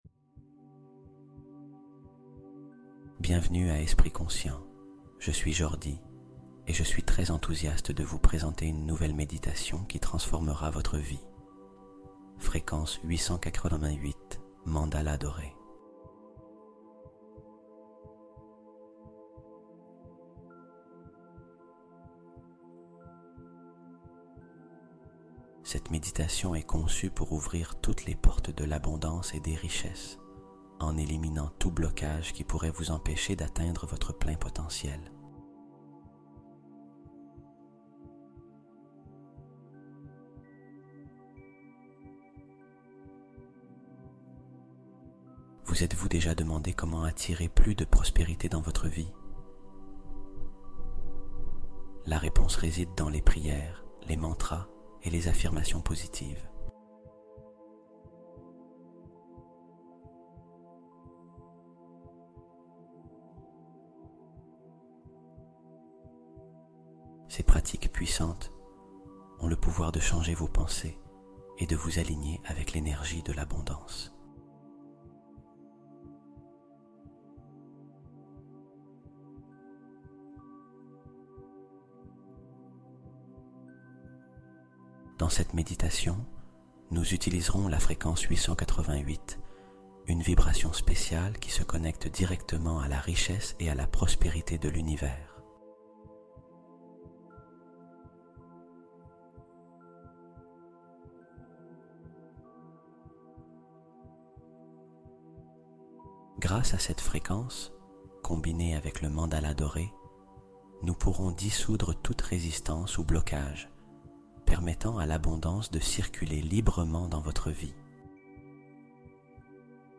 888 Hz Mandala Sacré : Ouvre Les Portes Dorées de l'Abondance et Détruis Tes Blocages